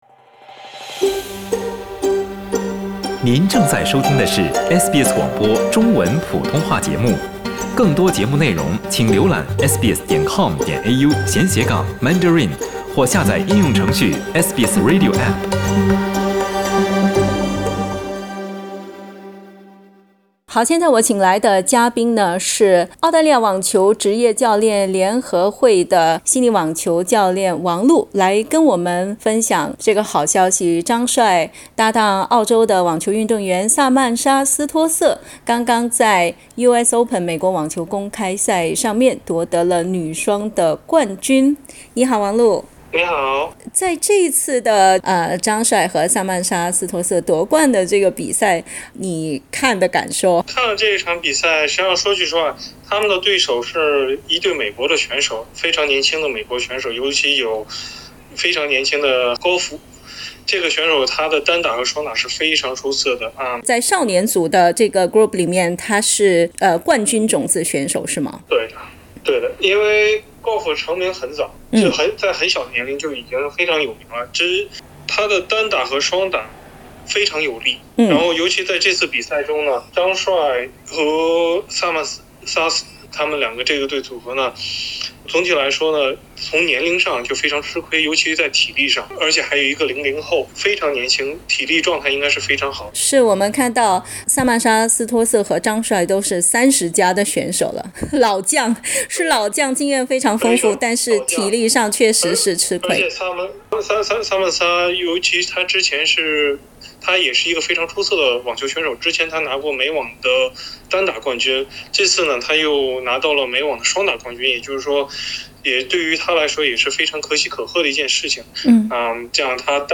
（點擊上圖收聽寀訪） 另據知情人士透露，張帥在奪冠之後，還沒來得及好好慶祝，就已經踏上前往歐洲的旅途。